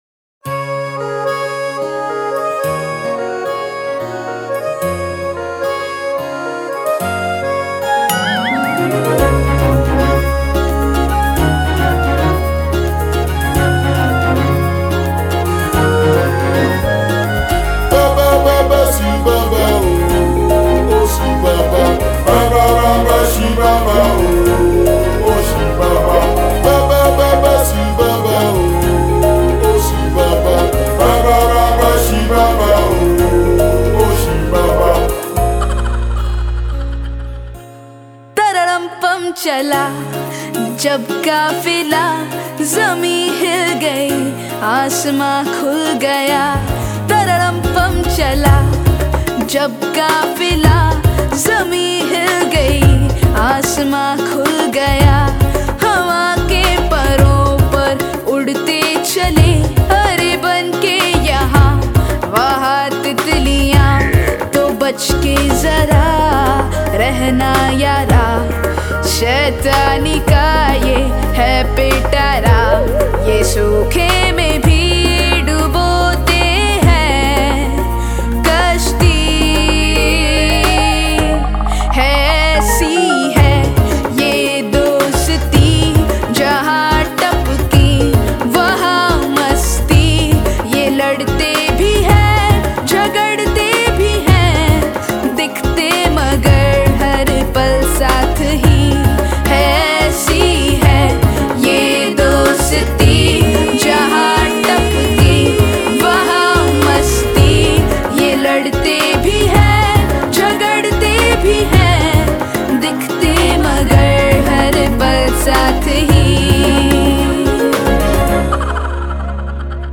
Bollywood Mp3 Music 2016